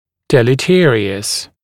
[ˌdelɪ’tɪərɪəs][ˌдэли’тиэриэс]вредный, вредоносный, опасный